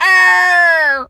pgs/Assets/Audio/Animal_Impersonations/bird_vulture_croak_06.wav at master
bird_vulture_croak_06.wav